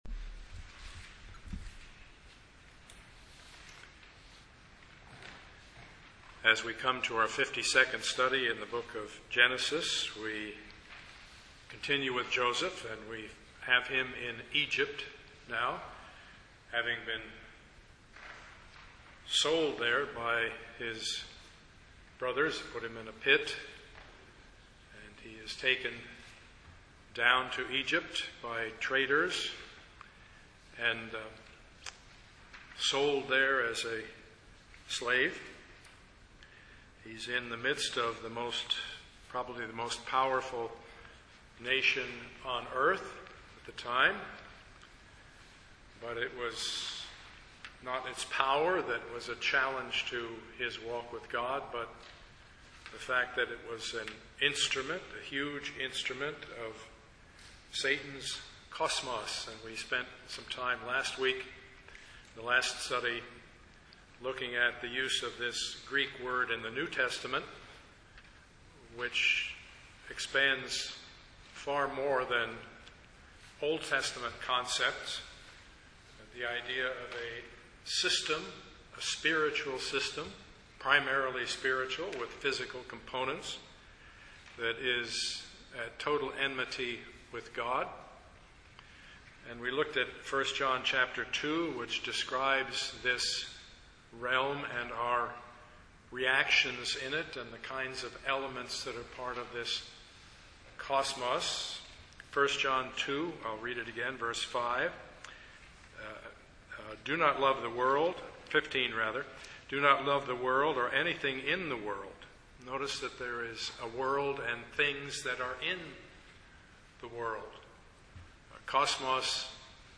Service Type: Sunday morning
Part 52 of the Sermon Series Topics: Blessing , Joseph , The World